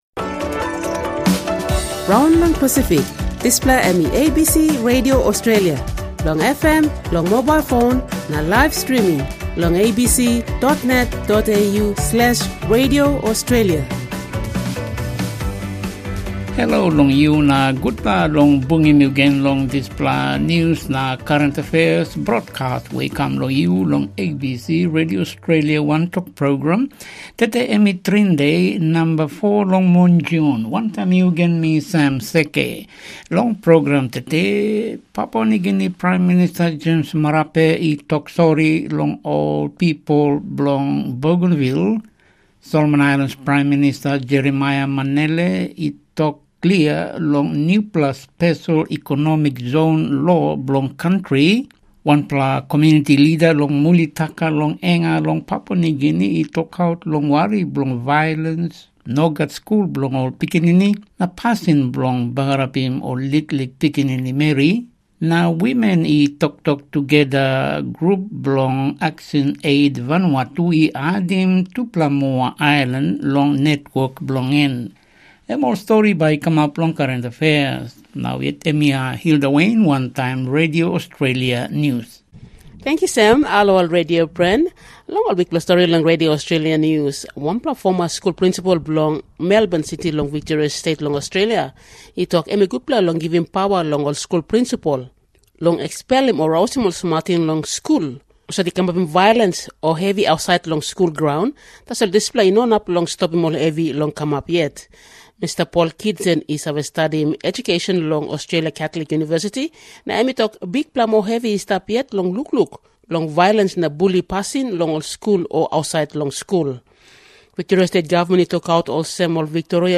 The Wantok Program is 30 minutes of news and current affairs broadcast on Radio Australia twice a day Monday to Friday in Papua New Guinea Tok Pisin, Solomon Islands Pijin and Vanuatu Bislama pidgin languages.